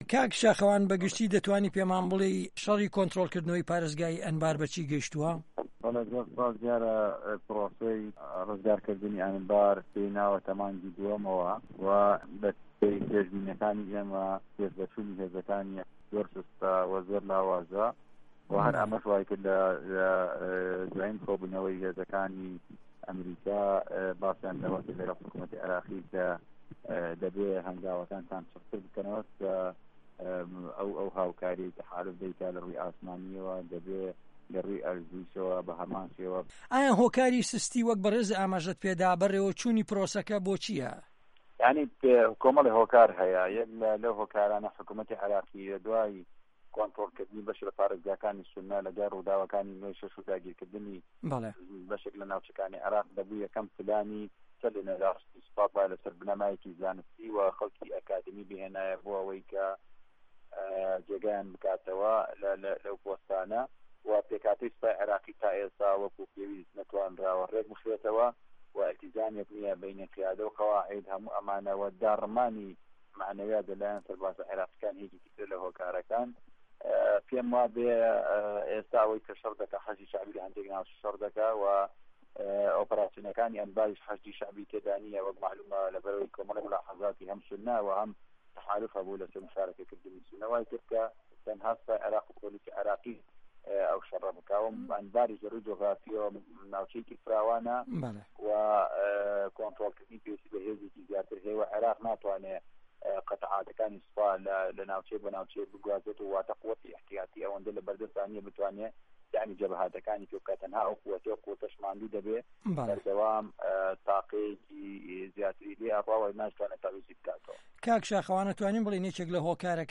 وتوێژ لەگەڵ شاخەوان عەبدوڵا